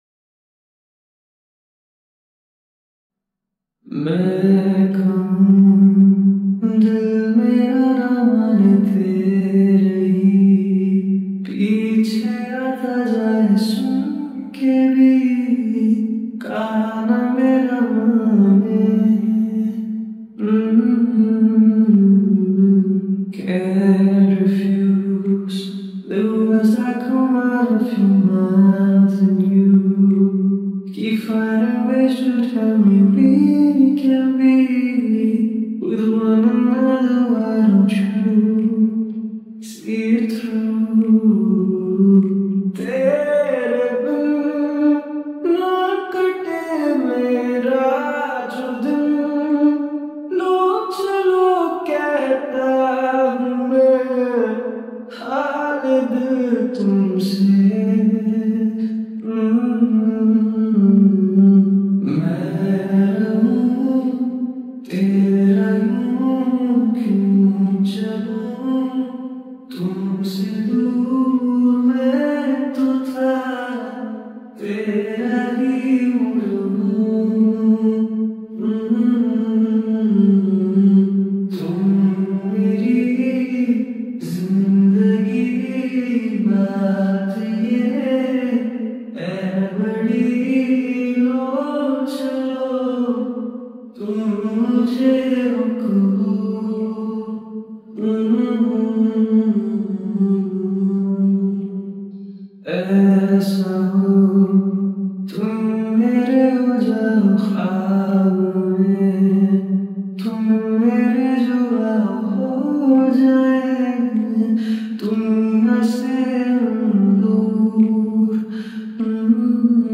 Vocals only
Slowed Reverb Pakistani drama OST. Cappella.